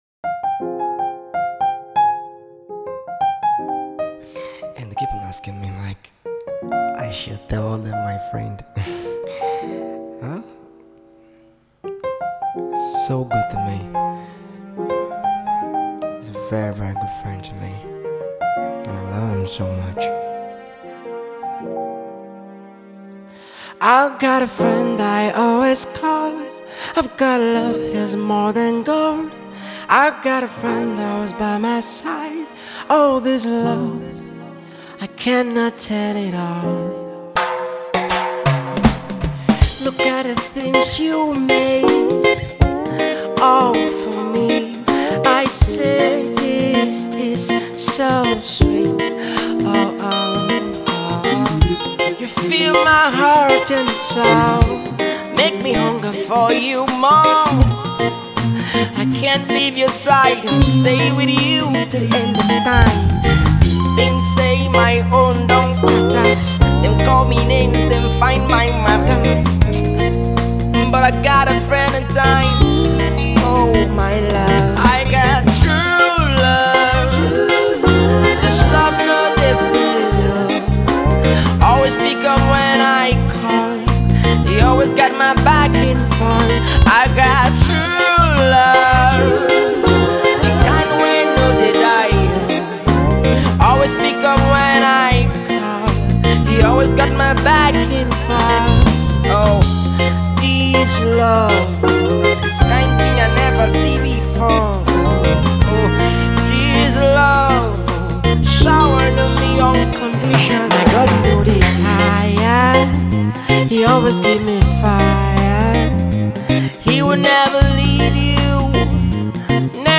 Gospel Jazz/Neo-Soul